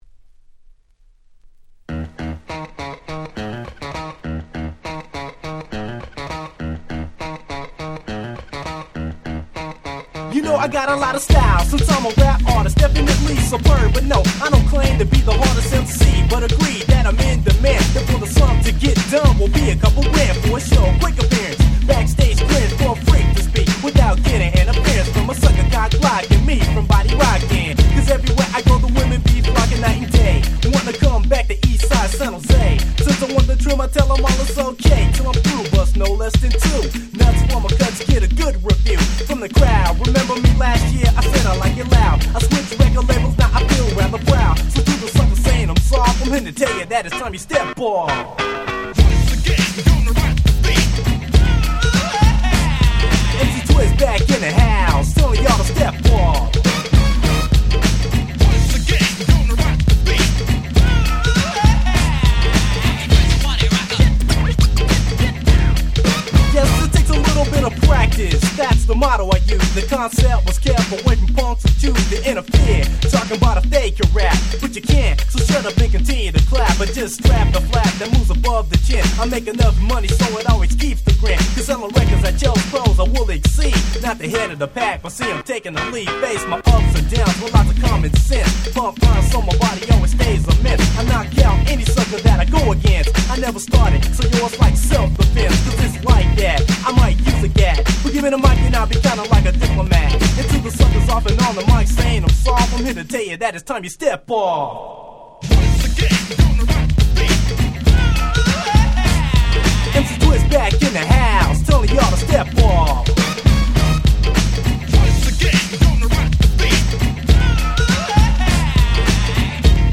90' Nice Hip Hop !!
ゴーゴー ツイスト 90's Boom Bap ブーンバップ